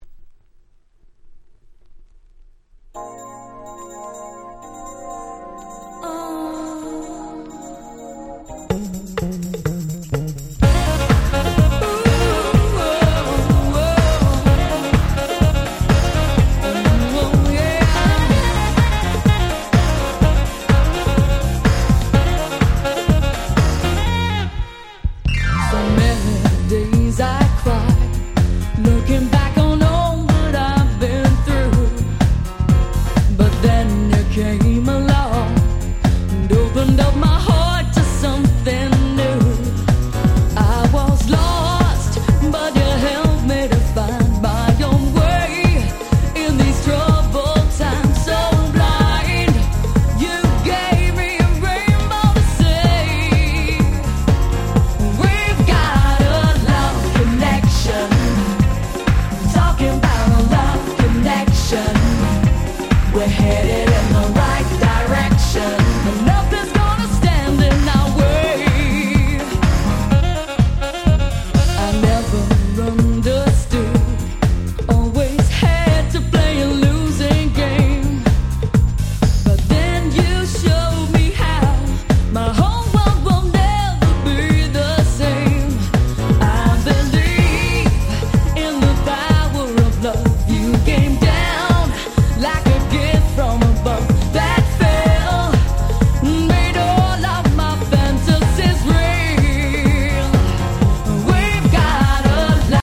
93' Very Nice R&B / Vocal House !!
哀愁漂う爽快なVocalが堪らない珠玉の歌モノ！！
ハウス